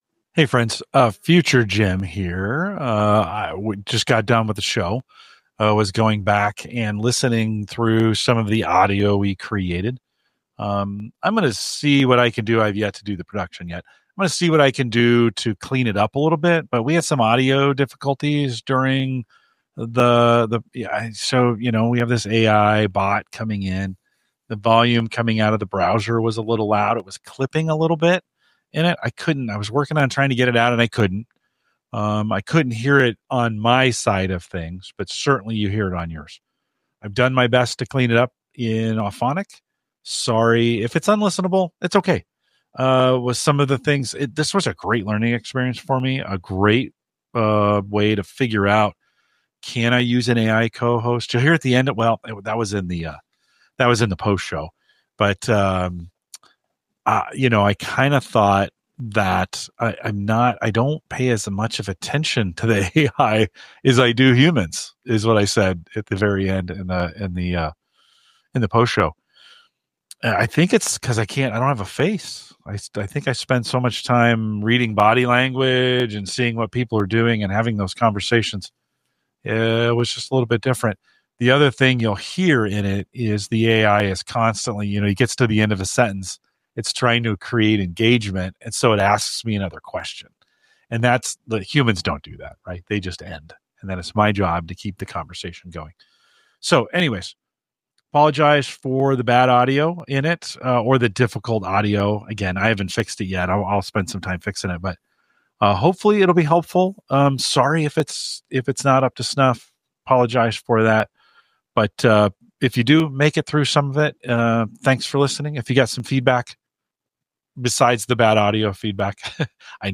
This week, I experiment with integrating AI into podcasting by introducing Microsoft’s Co-Pilot, Mick, as a co-host.
We discuss the initial struggles we face with audio quality; despite my efforts to manage the sound levels, Mick’s voice occasionally overpowers mine, reflecting a need for further refinement in using AI as a co-host.